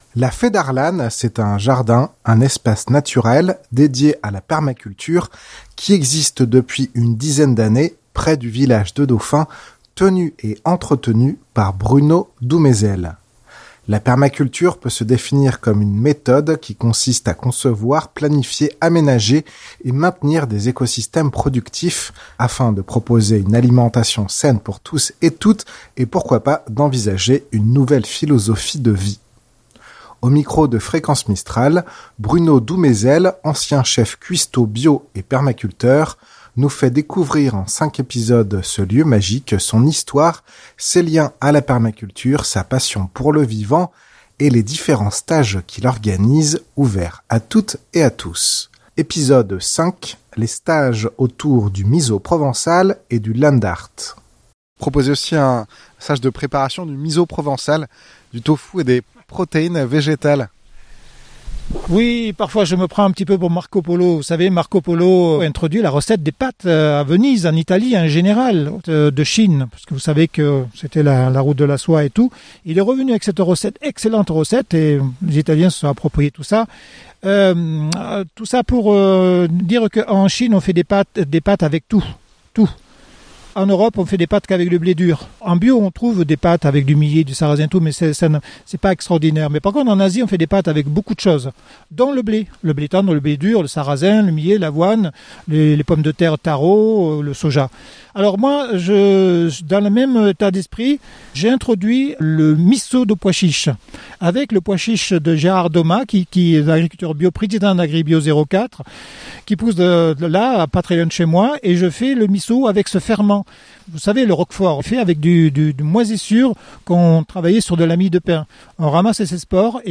Épisode 5: les stages de stage autour du miso provençale et du land art Reportage